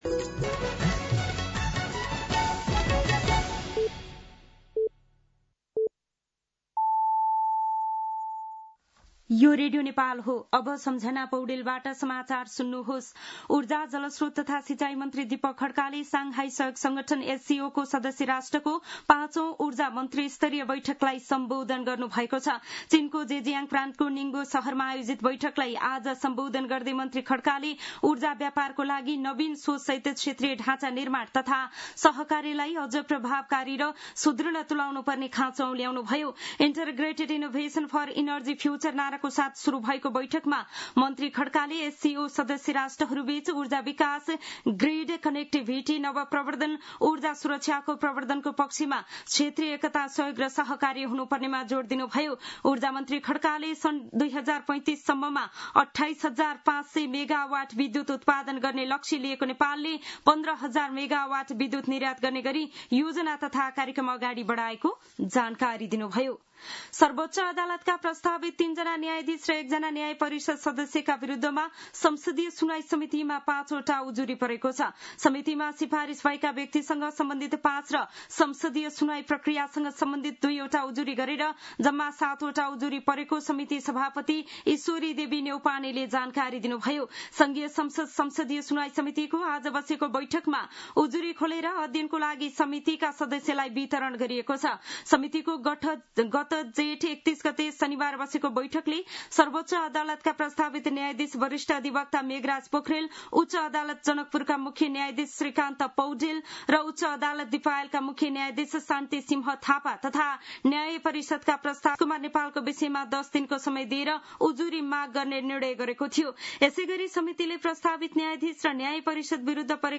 दिउँसो १ बजेको नेपाली समाचार : १२ असार , २०८२